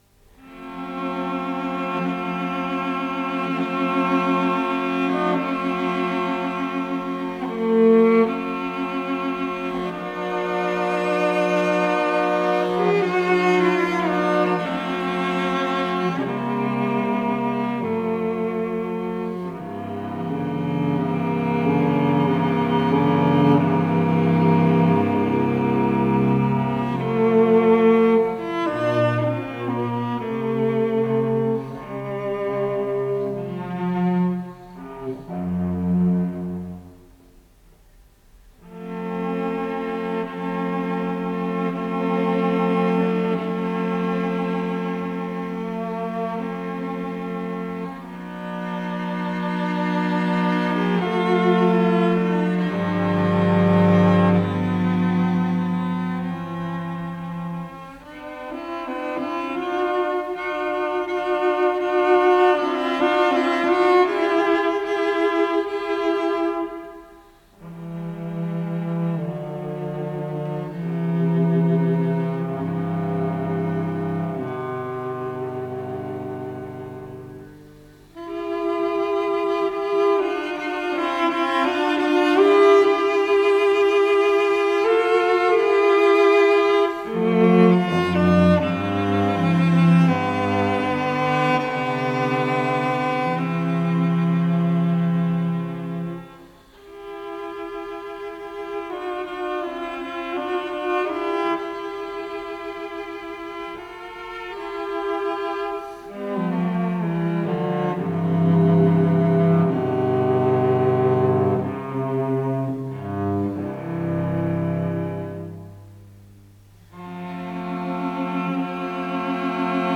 с профессиональной магнитной ленты
Ларго
ВариантДубль моно